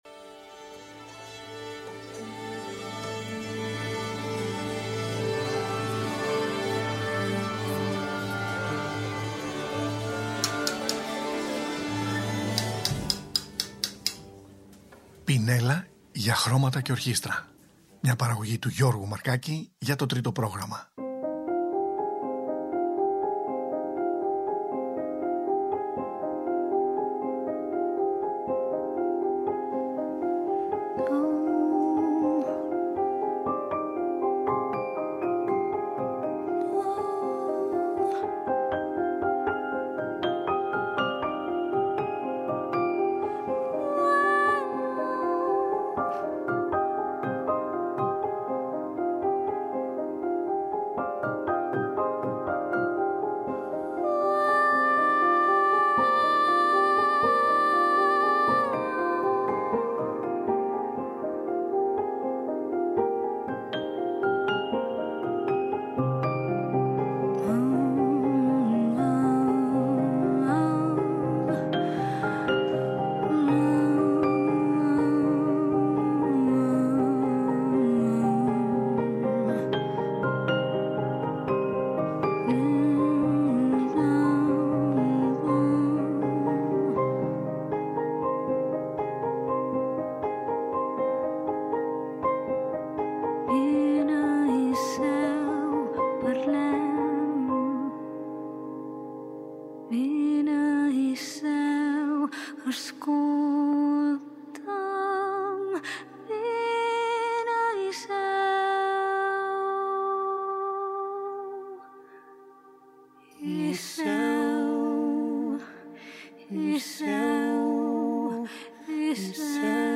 Συζήτηση με το σχήμα Alexandrae από την Καταλονία.